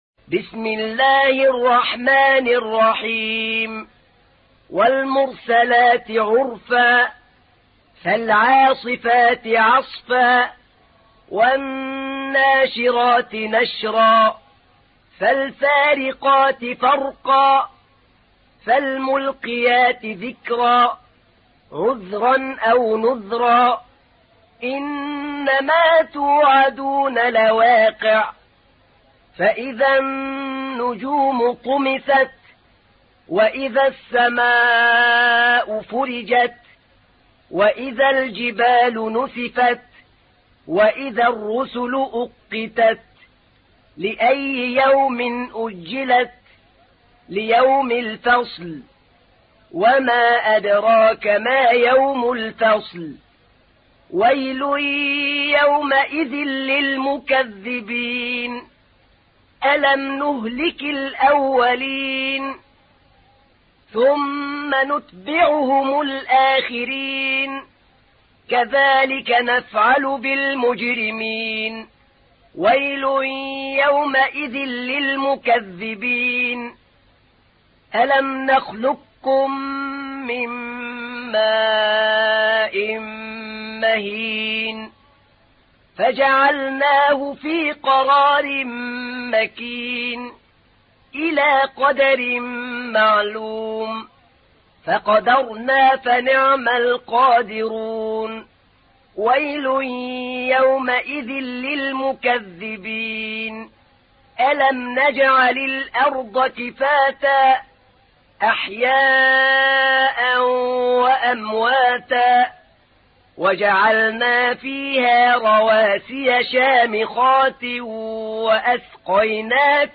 تحميل : 77. سورة المرسلات / القارئ أحمد نعينع / القرآن الكريم / موقع يا حسين